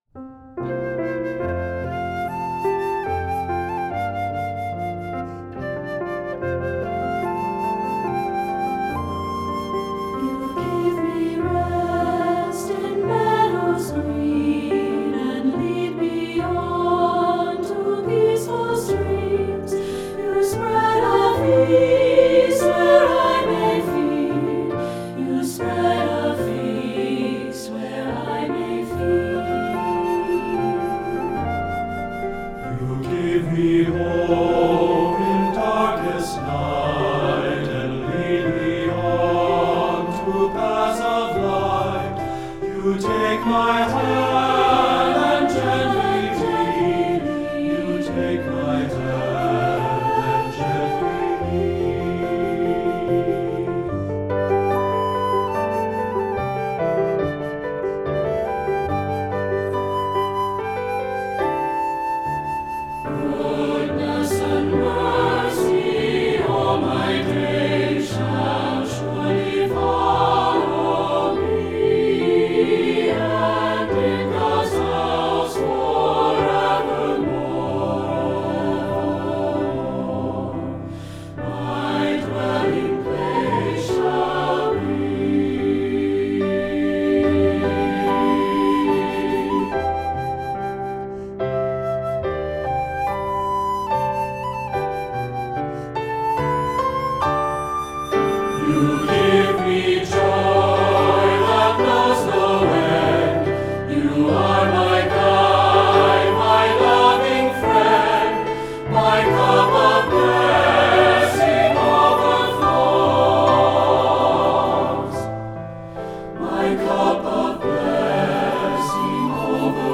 Voicing: SATB